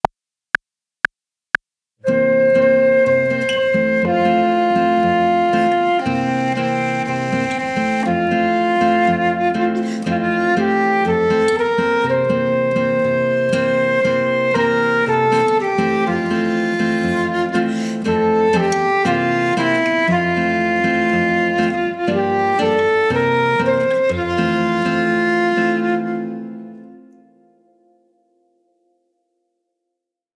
Gravado no LaMuSA, Laboratório de Música, Sonologia e Áudio da Escola de Música e Belas Artes do Paraná, Campus I da Universidade Estadual do Paraná – UNESPAR.